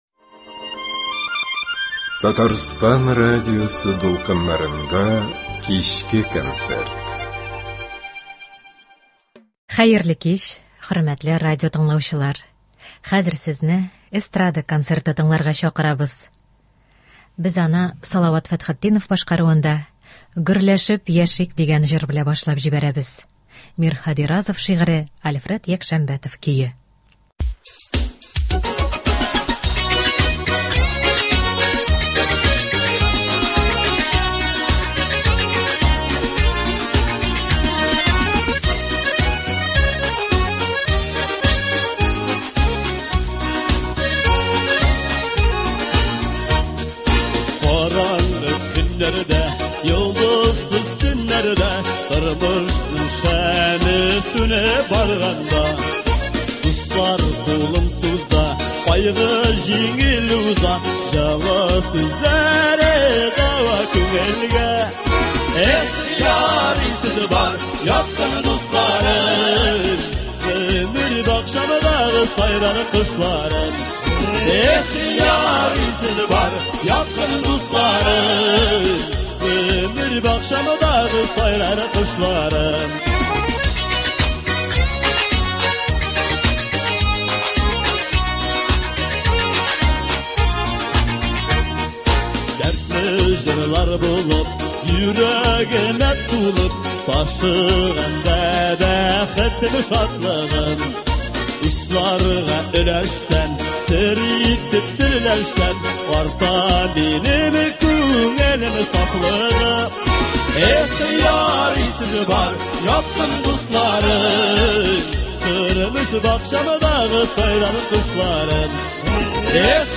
Эстрада концерты.